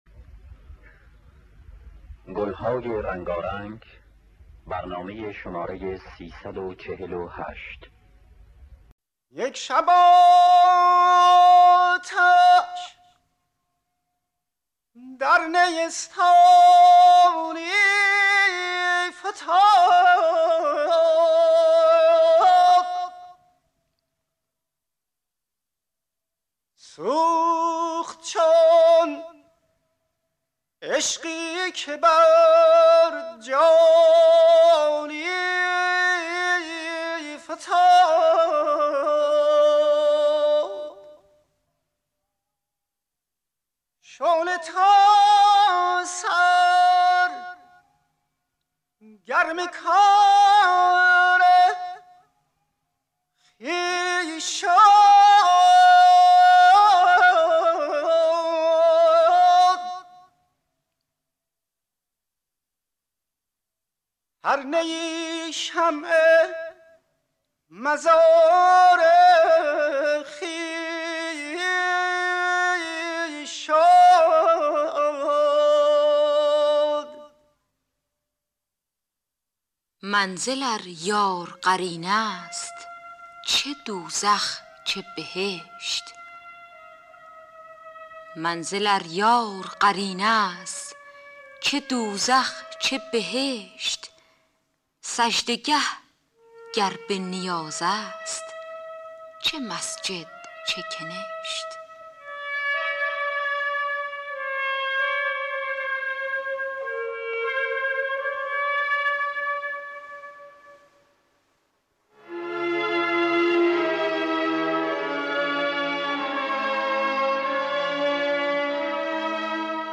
خوانندگان: پروین حسین قوامی نوازندگان: جهانگیر ملک جواد معروفی